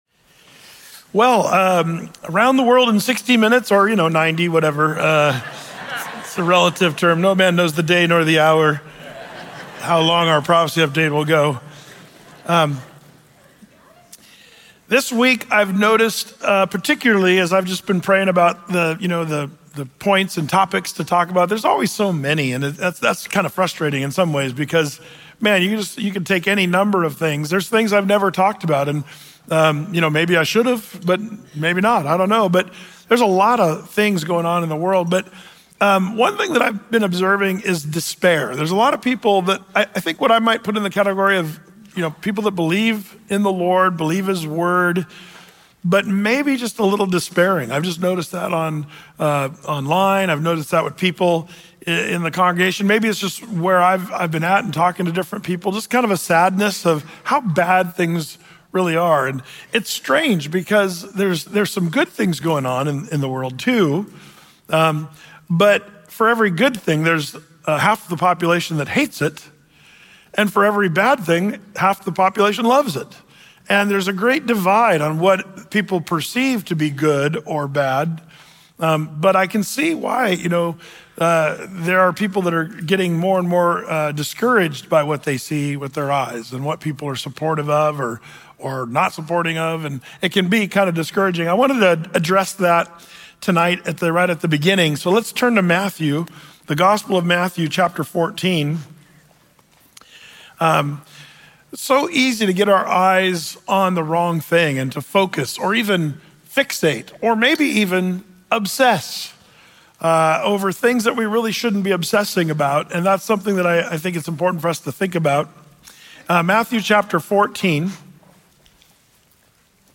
Through-the-Bible teaching